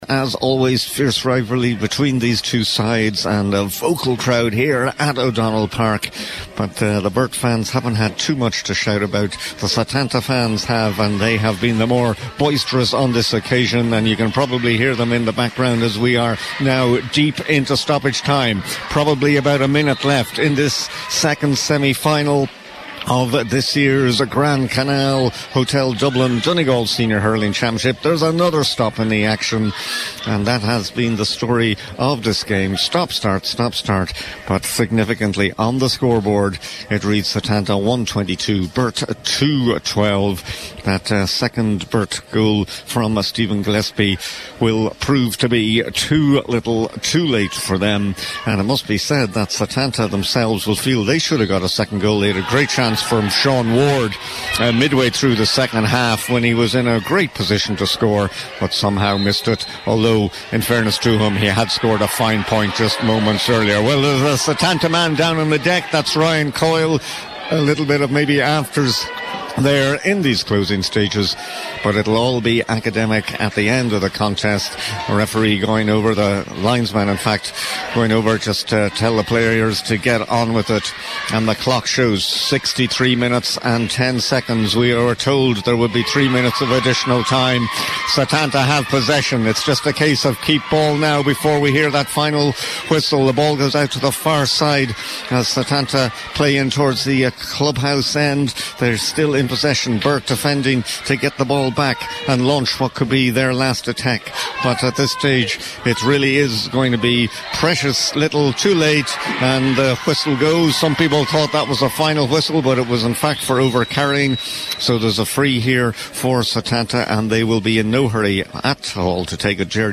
was live at full time